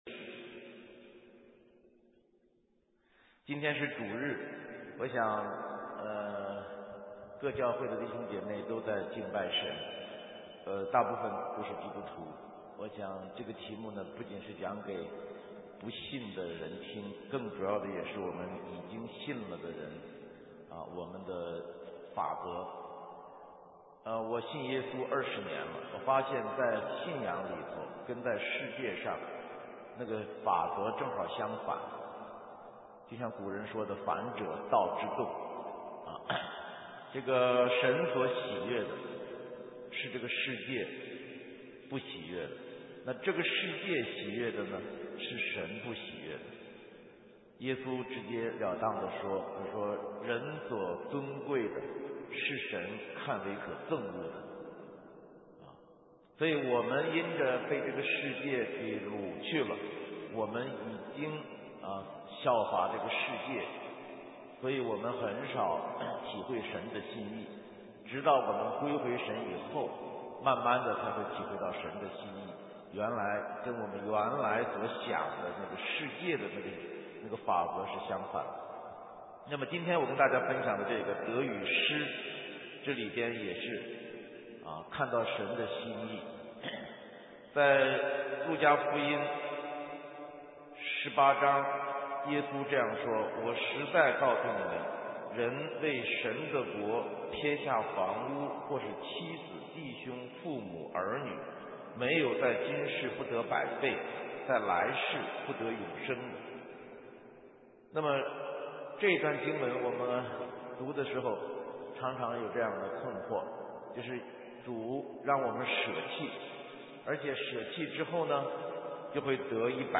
神州宣教--讲道录音 浏览：远志明洛杉矶西区布道会 - 得与失 (2010-07-11)
下载(9.27M) -------------------------------------------------------------------------------- 远志明洛杉矶西区布道会 - 得与失 (2010-07-11) --------------------------------------------------------------------------------